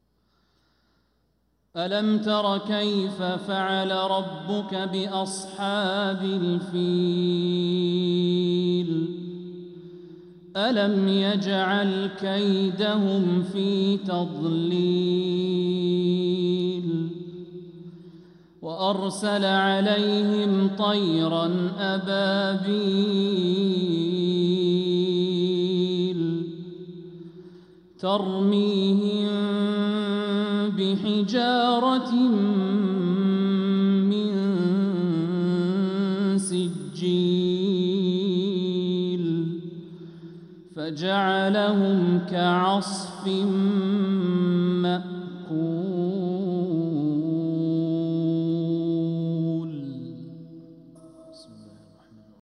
سورة الفيل | فروض ربيع الأخر 1446هـ > السور المكتملة للشيخ الوليد الشمسان من الحرم المكي 🕋 > السور المكتملة 🕋 > المزيد - تلاوات الحرمين